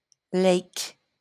bryt., amer.: IPA/leɪk/